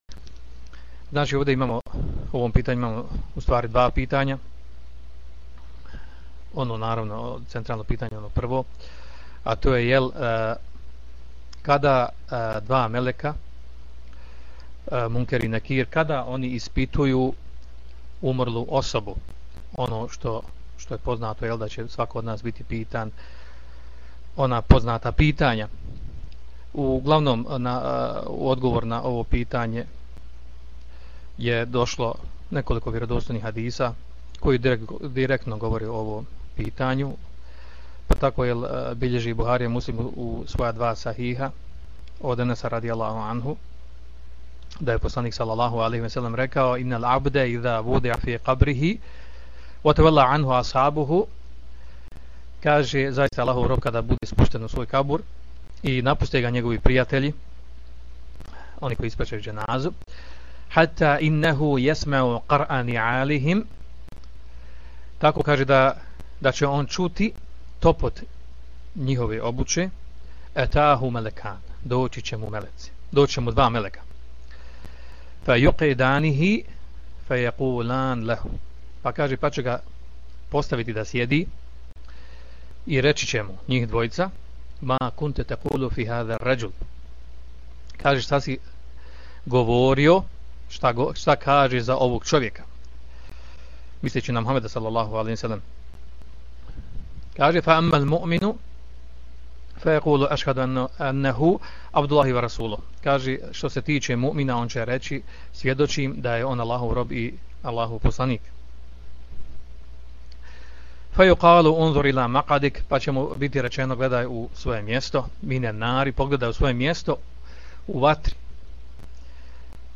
Poslušajte audio isječak iz predavanja